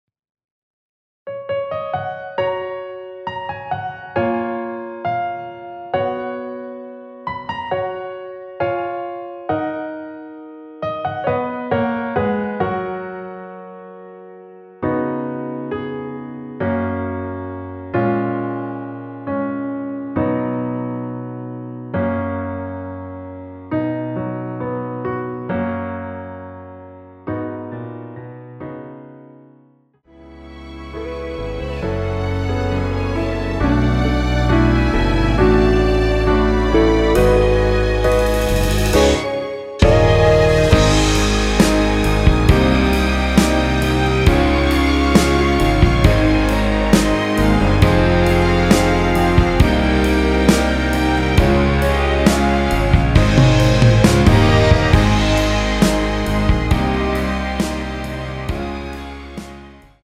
원키에서(+4)올린 MR입니다.
F#
앞부분30초, 뒷부분30초씩 편집해서 올려 드리고 있습니다.
중간에 음이 끈어지고 다시 나오는 이유는